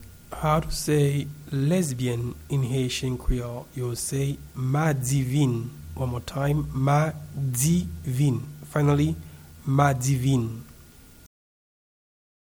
Pronunciation and Transcript:
Lesbian-in-Haitian-Creole-Madivin-pronunciation.mp3